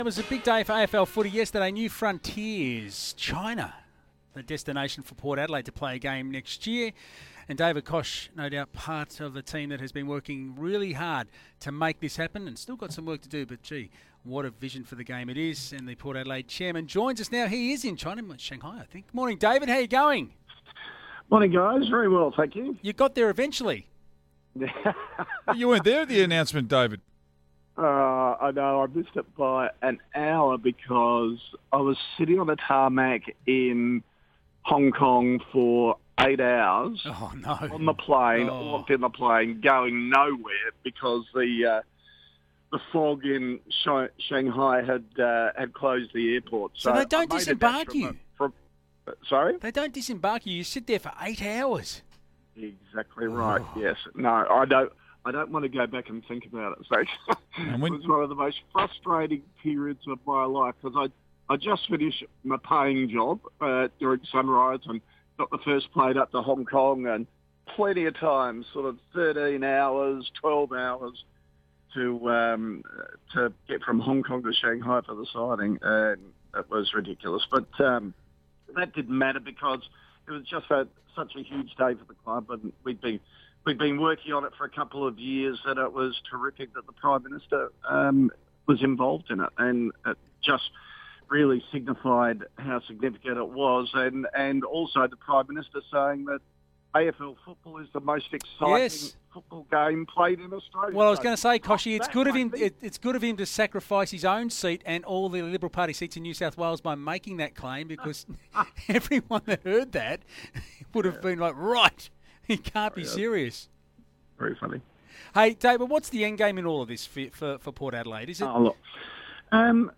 Port Adelaide Chairman David Koch talks to SEN about the club's historic China deals.